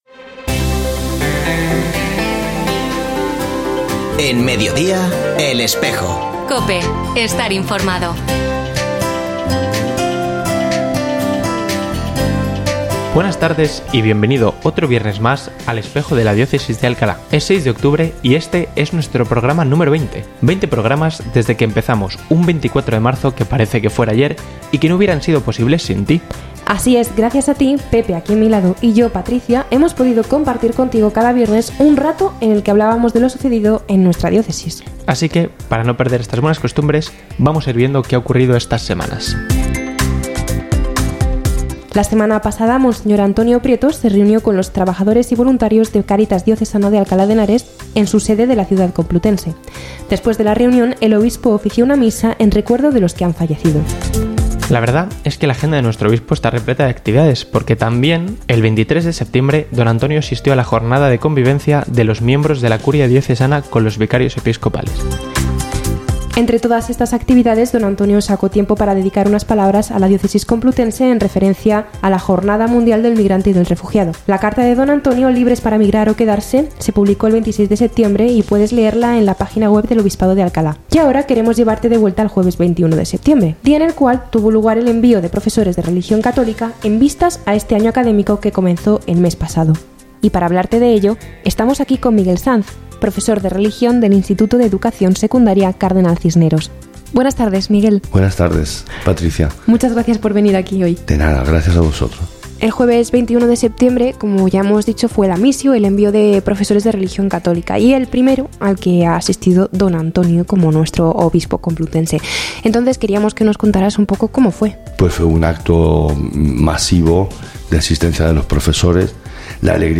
Ofrecemos el audio del programa de El Espejo de la Diócesis de Alcalá emitido hoy, 6 de octubre de 2023, en radio COPE. Este espacio de información religiosa de nuestra diócesis puede escucharse en la frecuencia 92.0 FM, todos los viernes de 13.33 a 14 horas.
Hoy, Mons. Prieto Lucena lo dedica a nuestro ángel custodio, invitándonos a acudir a él especialmente en momentos de dificultad.